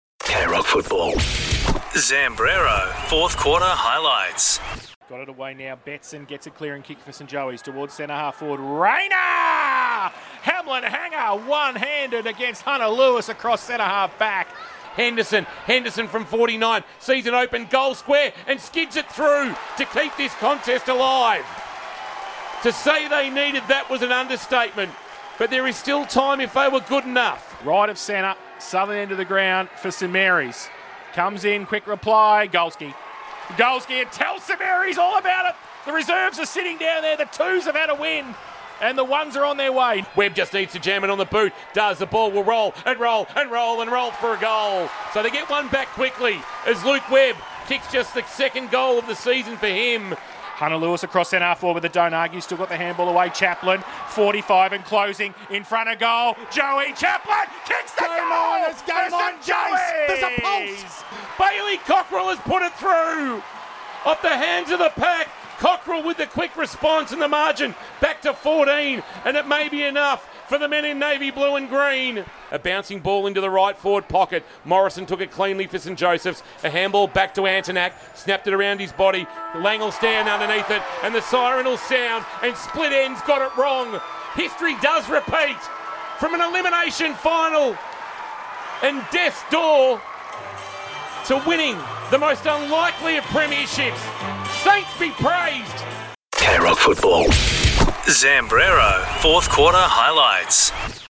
2025 - GFNL - Grand Final - St Joseph's vs. St Mary's - 4th Quarter Highlights